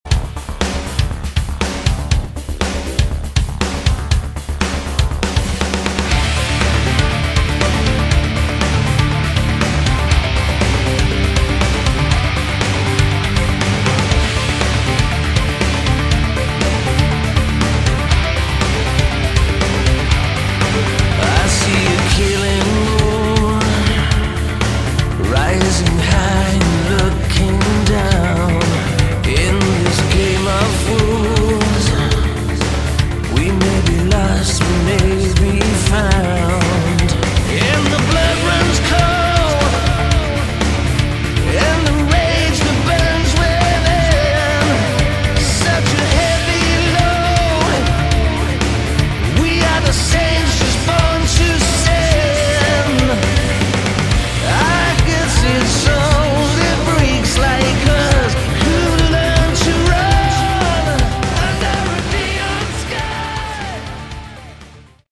Category: Melodic Rock
vocals, guitars, keyboards
lead guitars
bass, backing vocals
drums, percussion
saxophone